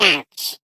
Sfx_tool_spypenguin_vo_hit_wall_14.ogg